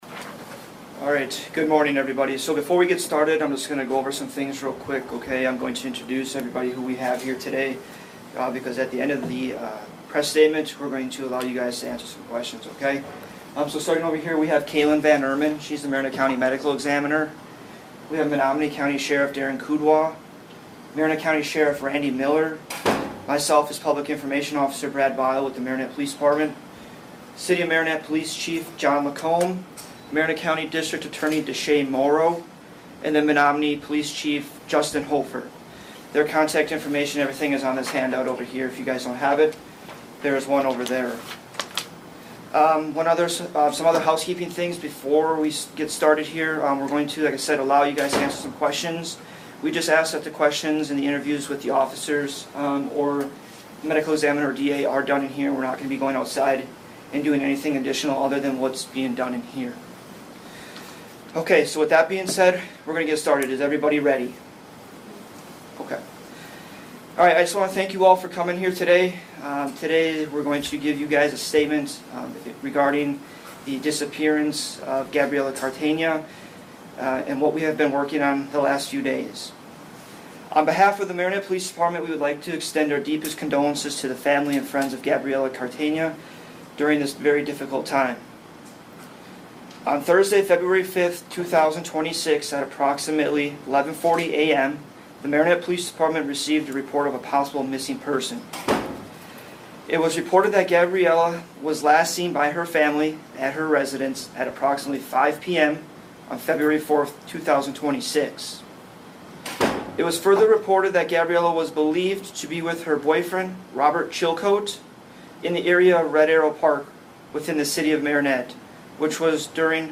CLICK TO HEAR STATEMENT FROM MARINETTE POLICE TO MEDIA
marinette-press-statement.mp3